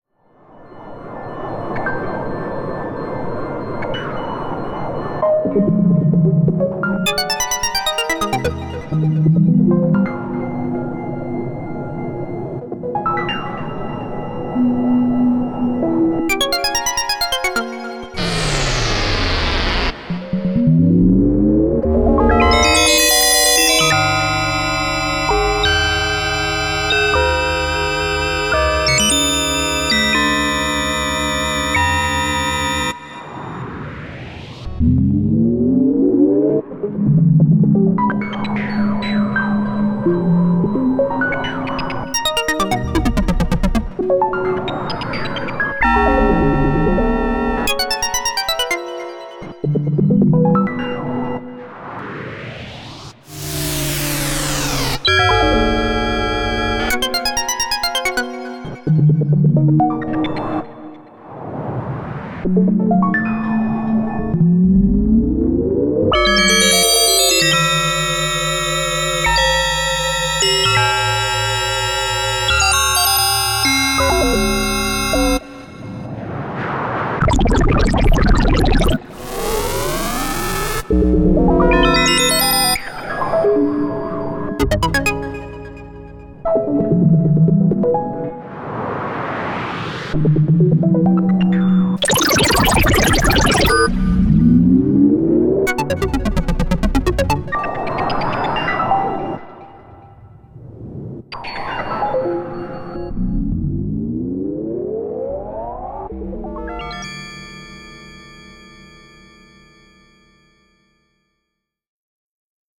24th Aug 2015 | Experiment
KORG AM8000R Multi Effector
Native Instruments REAKTOR Synthesizer / Software
Oberheim Matrix-1000 Synthesizer / Voltage-Controlled Oscillator